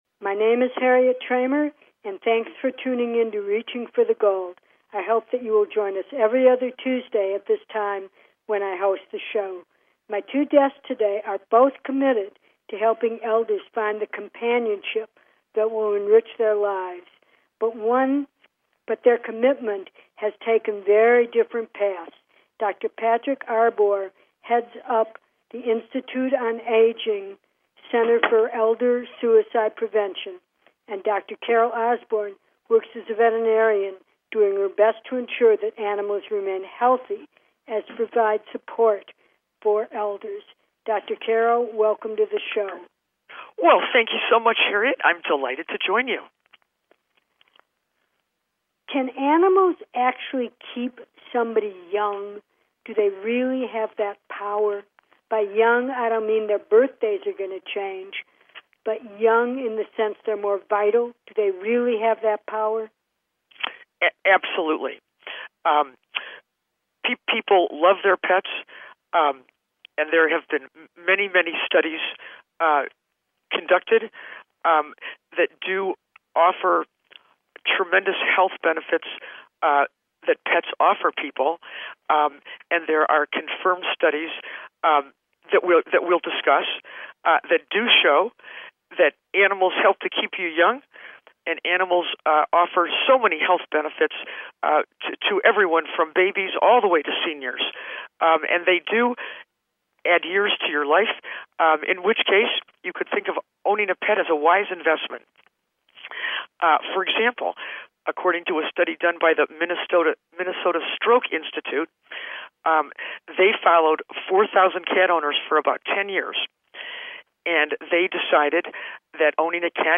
Talk Show Episode, Audio Podcast, Reaching For The Gold and Keeping them healthy on , show guests , about animals and aging,depression in elderly,suicide prevention,suicide prevent in elderly,Pet Care,pets and aging,pet therapy, categorized as Health & Lifestyle,Medicine,Pets and Animals,Psychology,Self Help